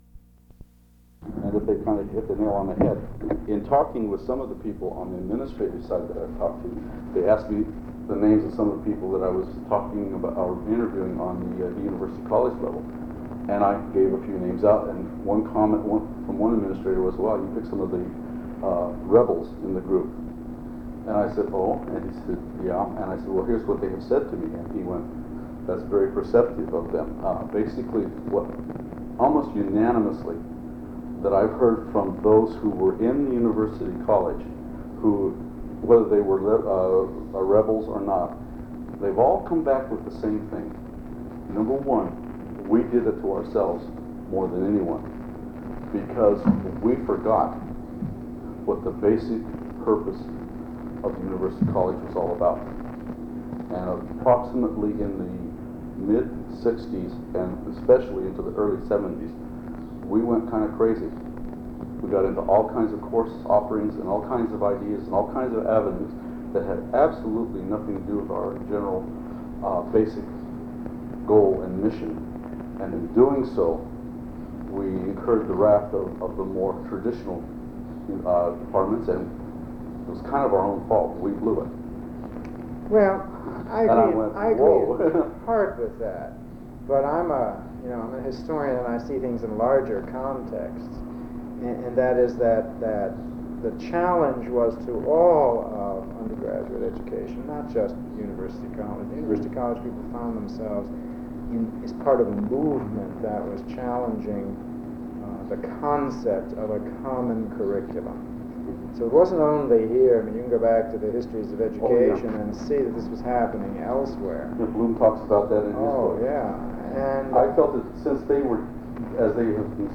Interview
Date: July 13, 1990 Format: Audio/mp3 Original Format: Audio cassette tape Resource Identifier: A008656 Collection Number: UA 10.3.156 Language: English Rights Management: Educational use only, no other permissions given.